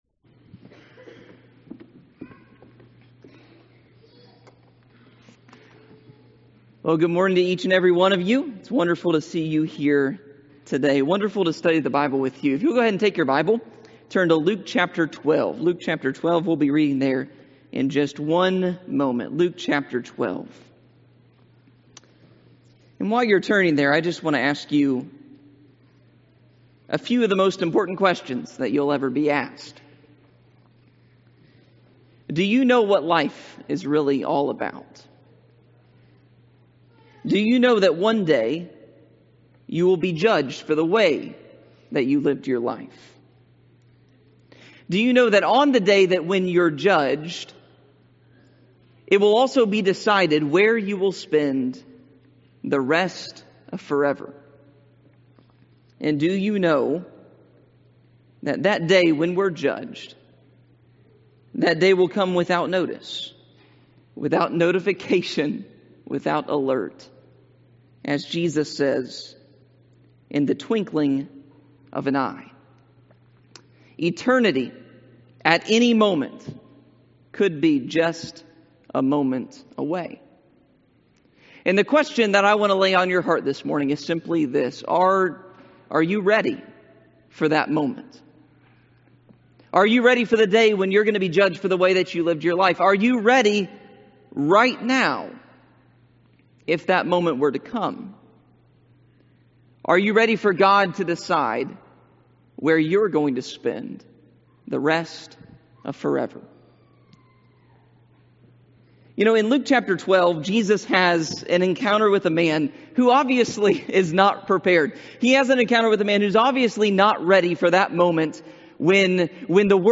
Sermons Are You Ready?